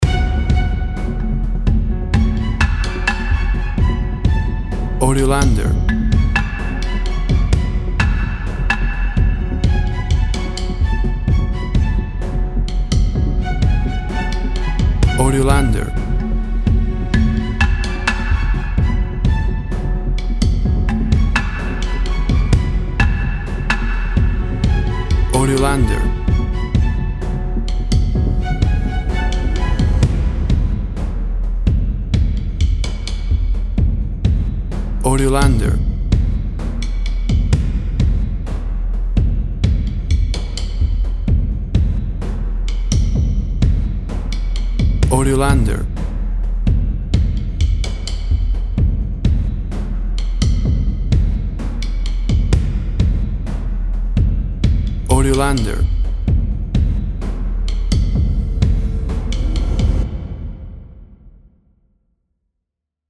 Great to build tension for reality based shows.
Tempo (BPM) 127